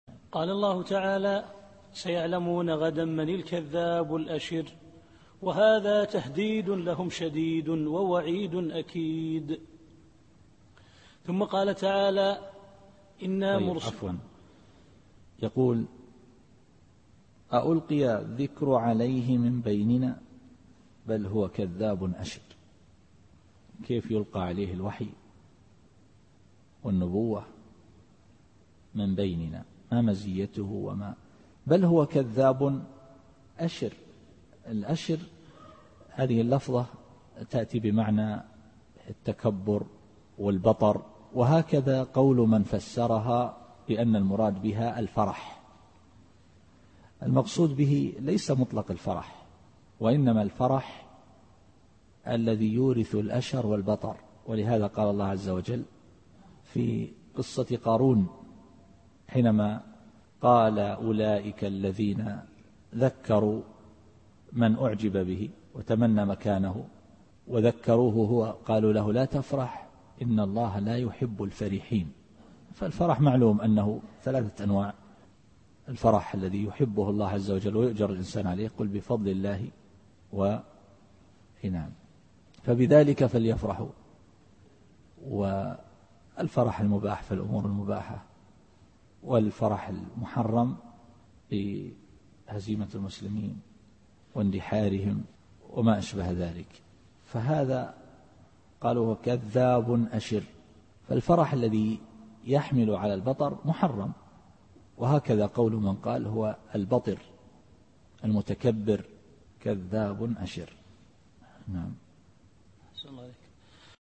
التفسير الصوتي [القمر / 26]